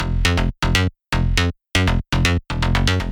Кстати, чуть позже заметил подобный характер в синте GMS и его тоже успешно воспроизвёл Серумом (во вложении).